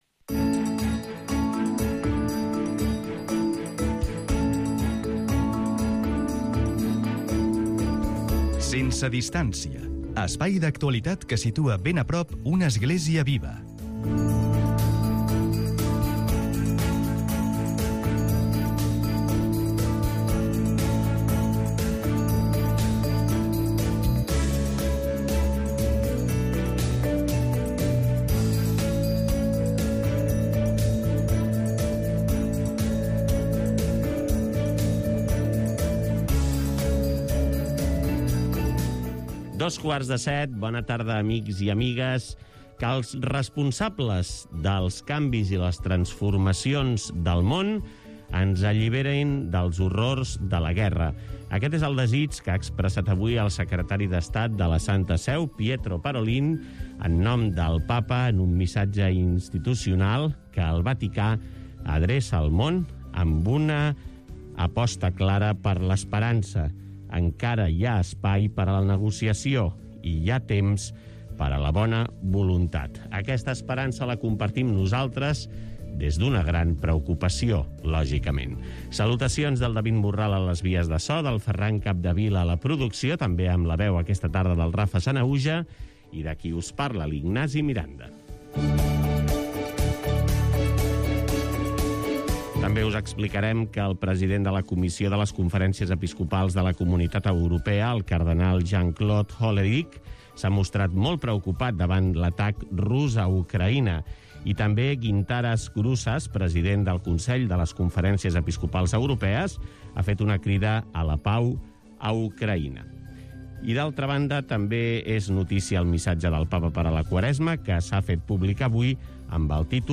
En una segona part, entrevistem un protagonista del dia i, en la tercera part, compartim una secció diària: 'La veu de Càritas' (dilluns), 'Temps de diàleg' (dimarts), 'Llevat dins la pasta' (dimecres), 'La litúrgia a prop' (dijous) i 'En sortida' (divendres).